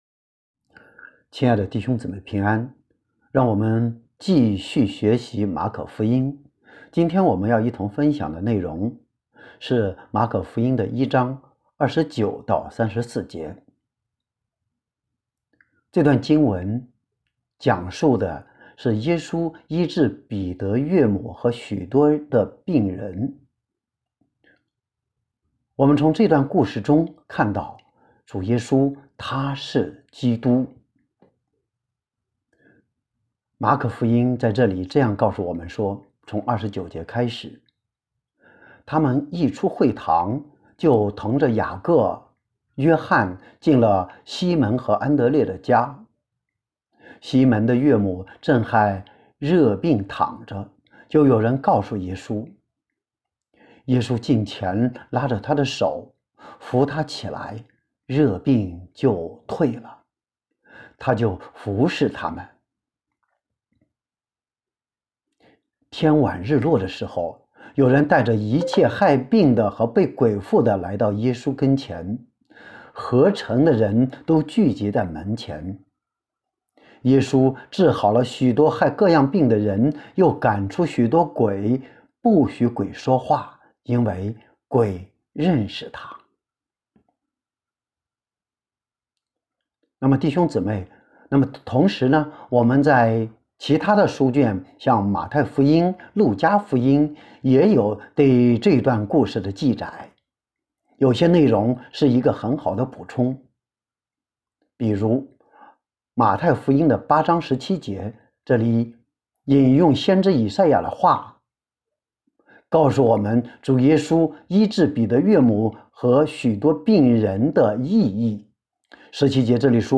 证道